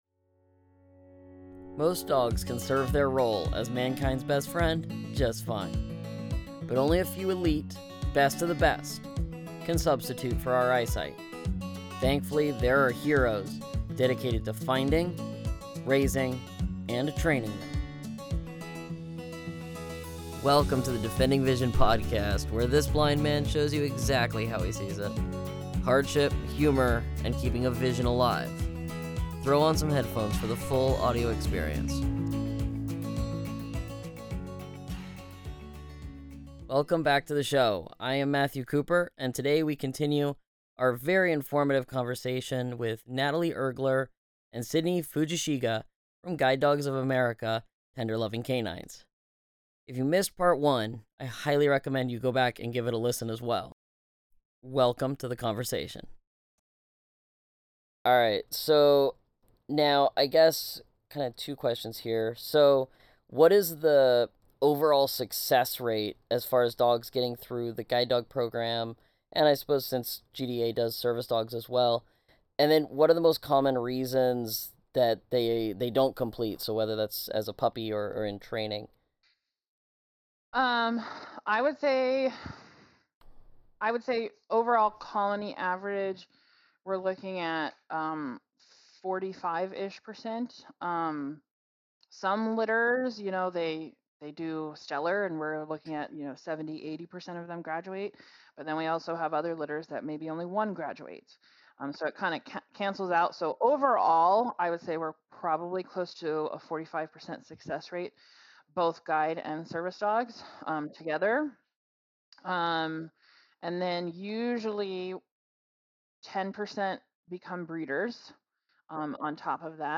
This is part two of a conversation with two people who know exactly what it takes.